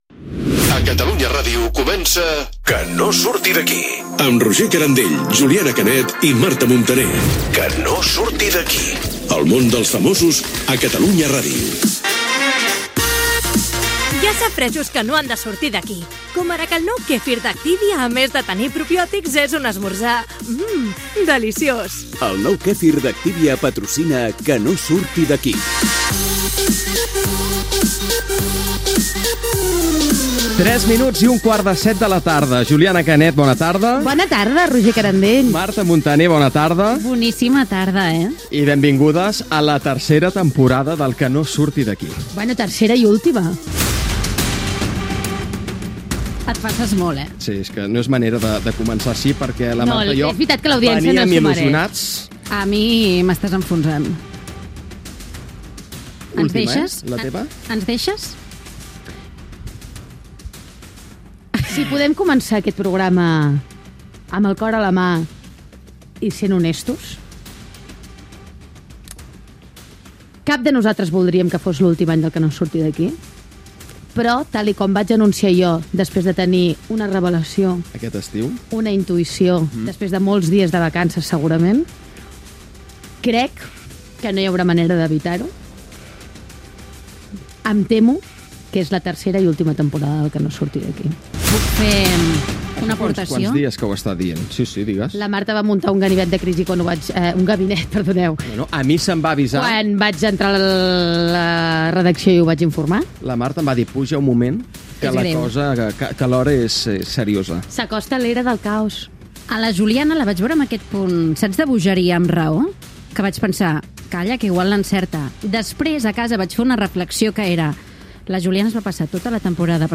Careta del programa, publicitat, hora, inici del primer programa de la tercera temporada del programa, amb canvi d'horari d'emissió. Diàleg sobre la intuició que pot ser l'última temporada del programa.
Entreteniment